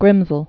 (grĭmzəl)